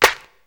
Dr Dre Claps+Snaps_41.wav